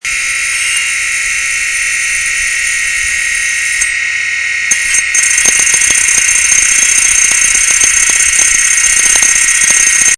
Vous trouverez ci-dessous quelques échantillons sonores qui illustrent ce que vous pouvez entendre lorsque votre disque dur présente des signes de faiblesses (ou plus, et donc certainement trop tard).
Différents disques produisent différents sons.
head_damage_4.mp3